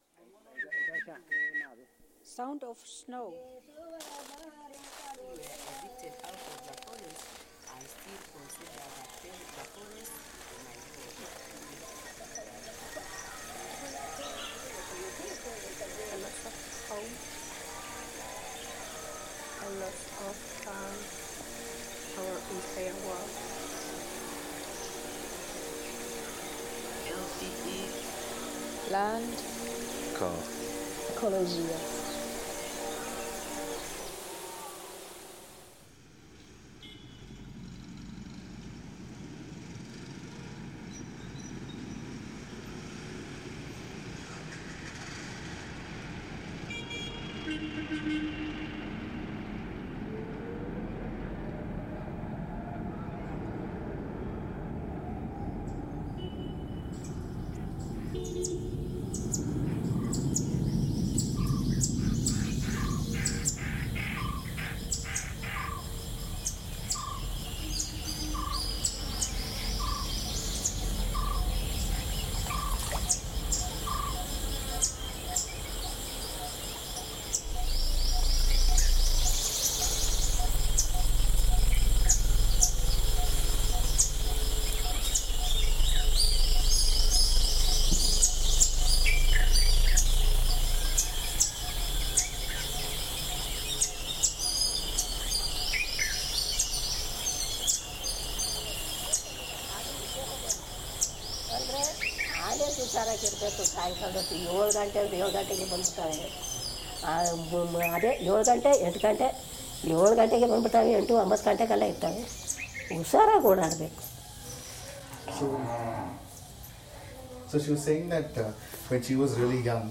This B-side is atmospheric journey through the world of the second Episode. It takes us to the forest of Mau in Kenya, the ancestral home of the Ogiek people and explores their honey keeping tradition which is integral to their culture, health and identity.